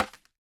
Minecraft Version Minecraft Version latest Latest Release | Latest Snapshot latest / assets / minecraft / sounds / block / bamboo / place4.ogg Compare With Compare With Latest Release | Latest Snapshot